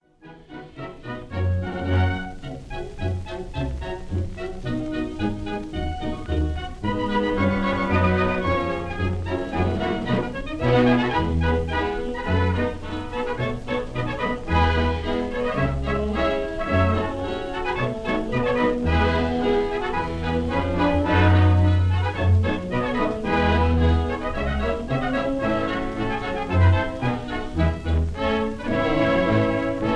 This is a 1928 recording